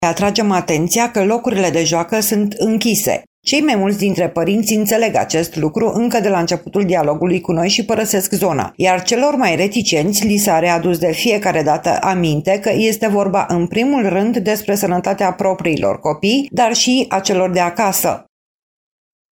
VOXURI-LOCURI-DE-JOACĂ.mp3